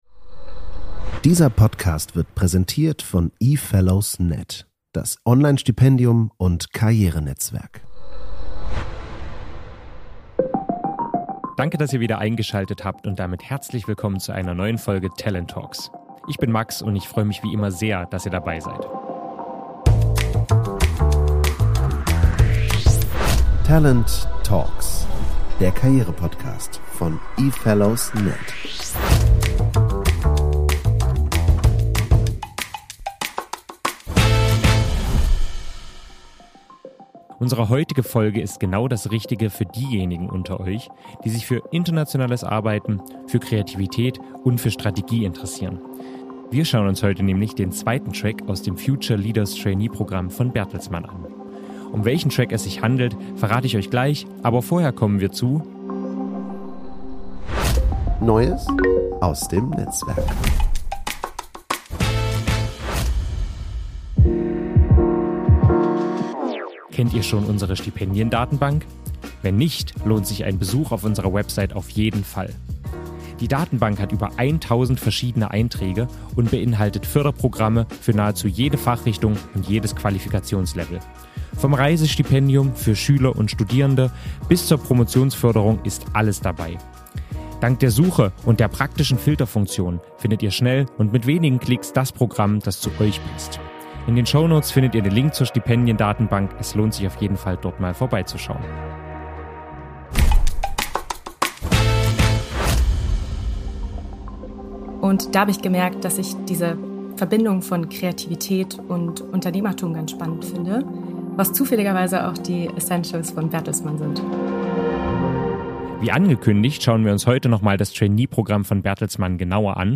Ein Gespräch über moderne Karrierewege, internationales Arbeiten und die Verbindung von Kreativität und Business im Konzern. Außerdem geht es um die Bewerbung für den Creative Management Track, um Haltung und Mindset beim Berufseinstieg und um die Frage, welche Rolle Künstliche Intelligenz in kreativen Berufen spielt.